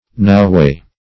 Noway \No"way`\, Noways \No"ways`\, No way \No" way`\adv.